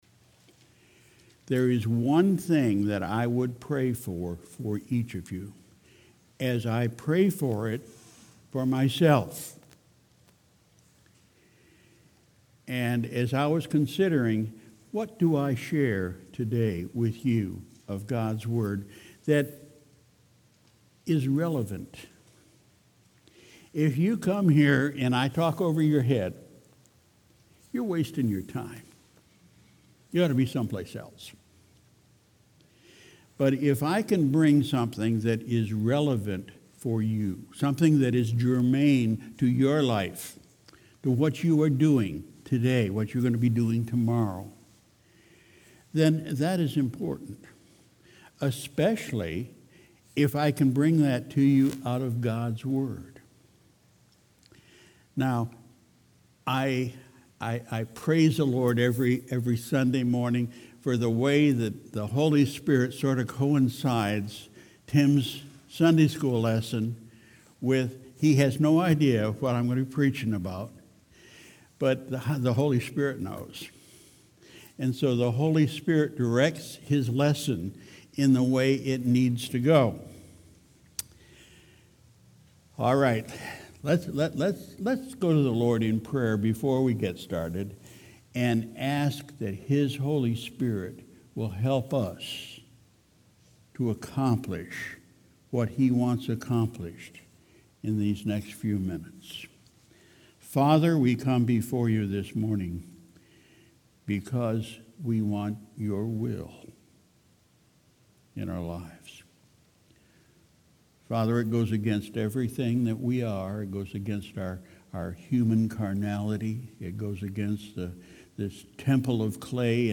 Sunday, July 14, 2019 – Morning Service